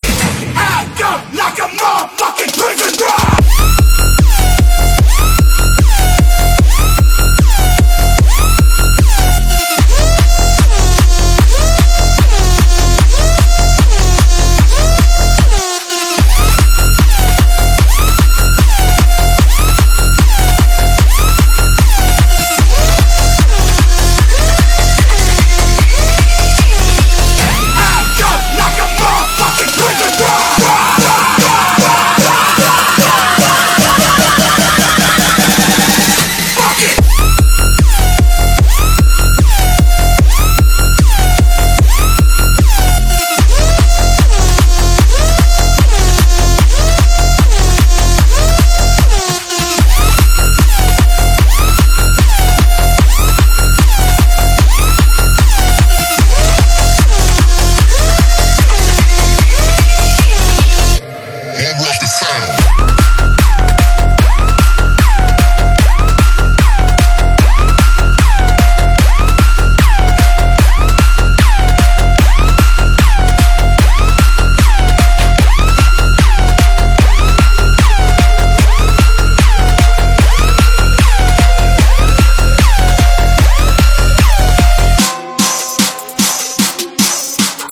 本铃声大小为542.5KB，总时长87秒，属于DJ分类。
蹦迪神曲